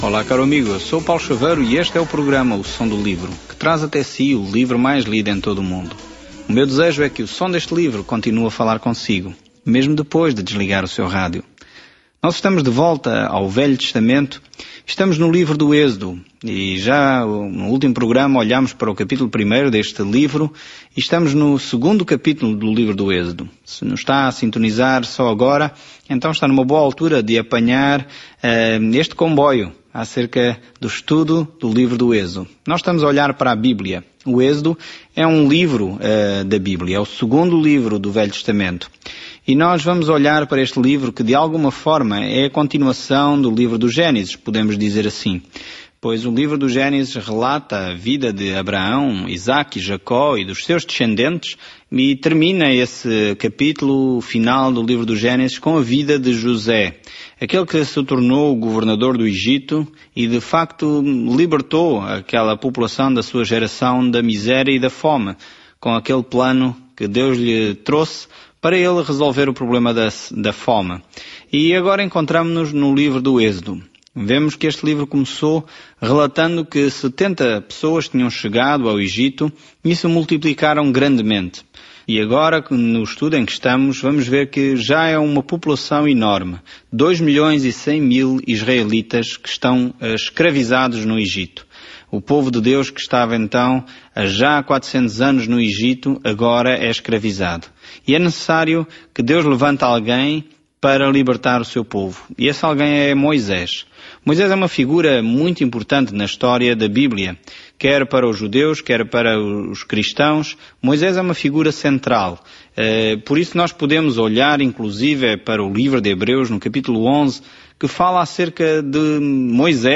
Scripture Exodus 2:1-21 Day 1 Start this Plan Day 3 About this Plan Êxodo traça a fuga de Israel da escravidão no Egito e descreve tudo o que aconteceu ao longo do caminho. Viaje diariamente pelo Êxodo enquanto ouve o estudo em áudio e lê versículos selecionados da palavra de Deus.